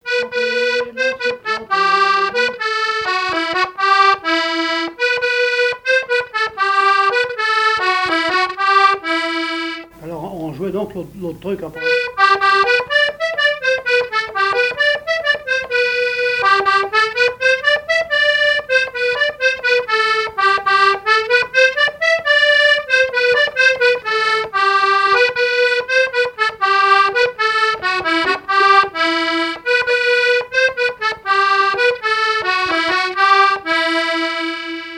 Fonction d'après l'analyste gestuel : à marcher
Genre laisse
Pièce musicale inédite